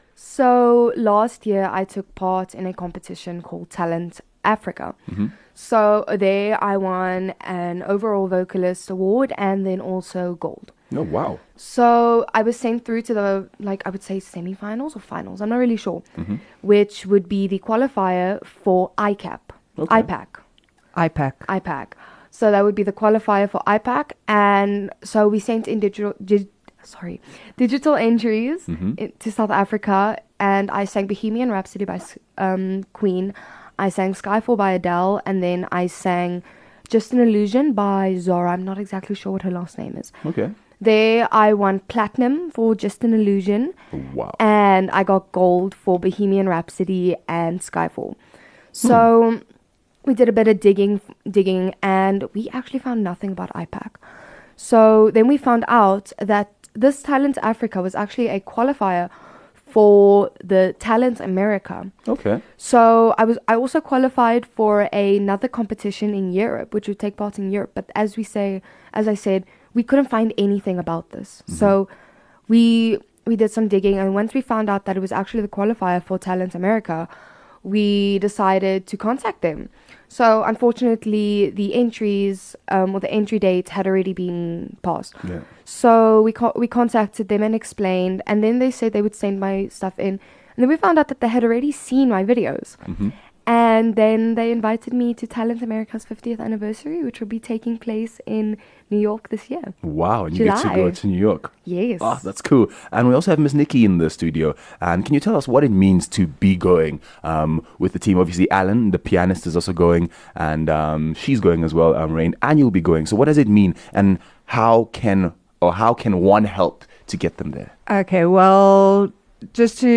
graces us with her beautiful voice